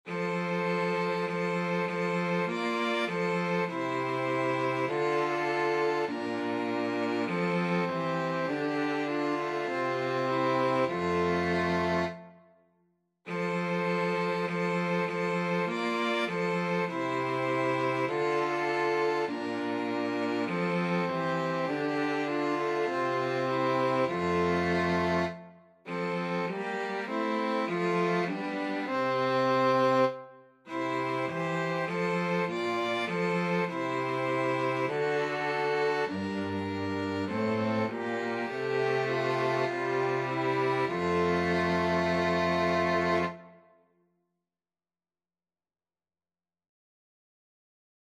Free Sheet music for 2-Violins-Cello
Violin 1Violin 2Cello
Traditional Music of unknown author.
4/4 (View more 4/4 Music)
F major (Sounding Pitch) (View more F major Music for 2-Violins-Cello )